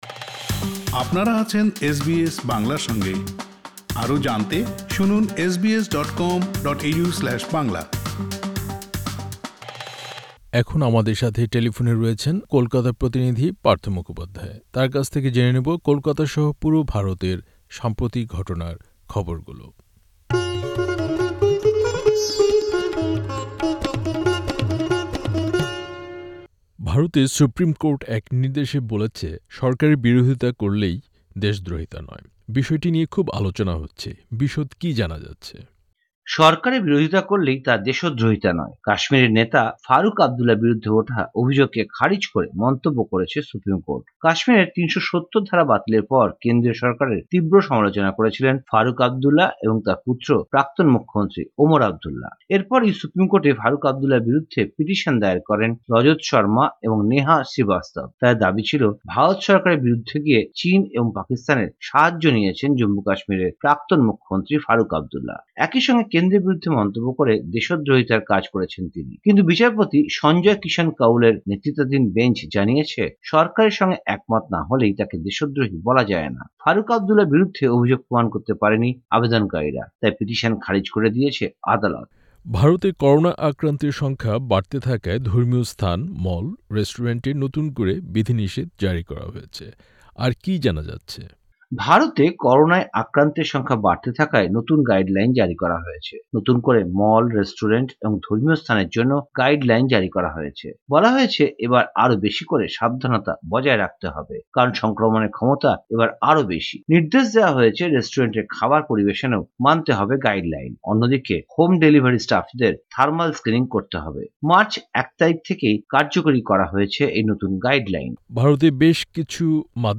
টেলিফোনে জানাচ্ছেন ভারতের সাম্প্রতিক ঘটনার খবর।